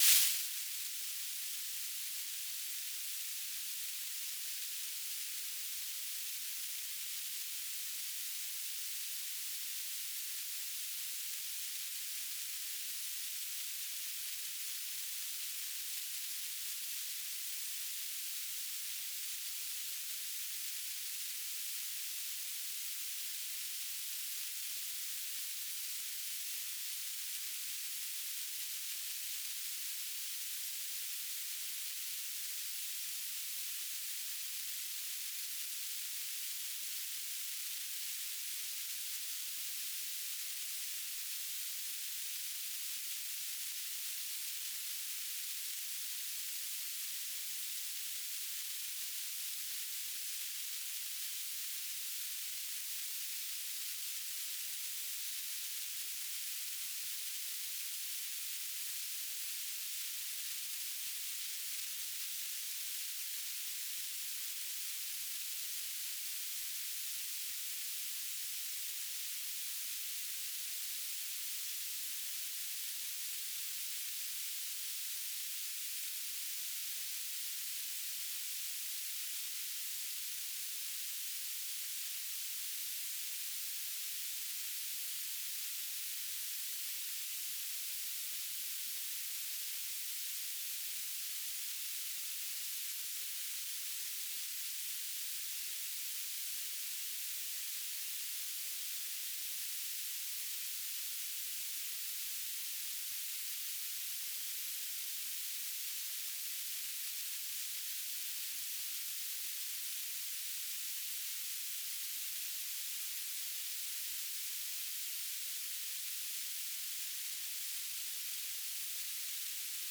"station_name": "Adelaide City VHF",
"transmitter_description": "BPSK1k2 TLM",
"transmitter_mode": "BPSK",